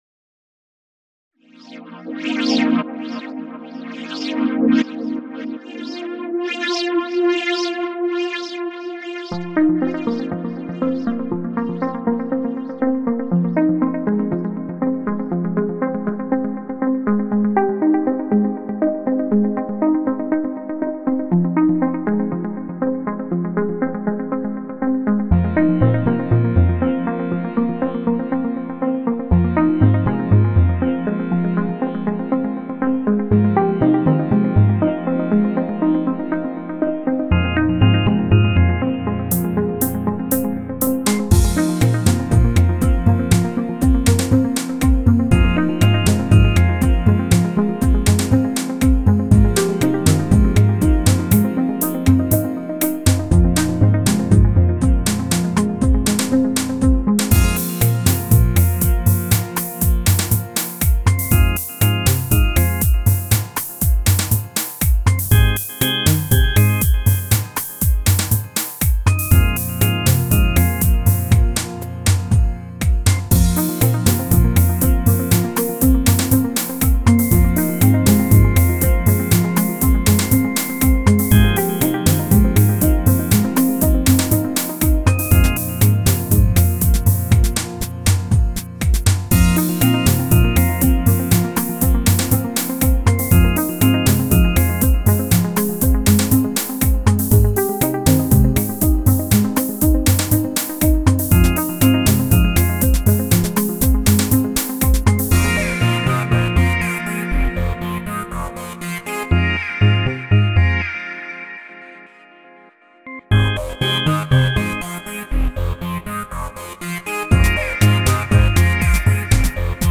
Genre Chillout